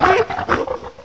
sovereignx/sound/direct_sound_samples/cries/crocalor.aif at master